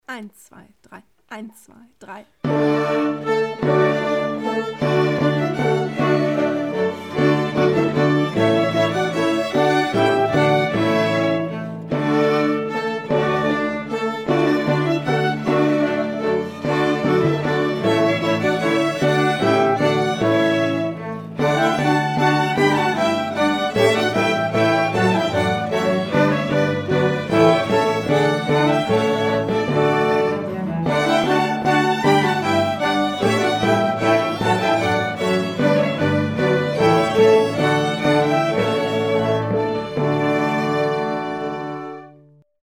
So ist das neueste Projekt eine Mehrspuraufnahme  von fünf Sätzen aus der Feuerwerksmusik von Händel, wo jede/r Schüler*in zu einem vorgefertigten Playback einzeln seine/ihre Stimme einspielt.
Playback 2) und nach einigen Wochen klingt das Playback schon fast so als würde man im  Orchester sitzen (